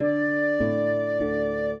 flute-harp
minuet15-2.wav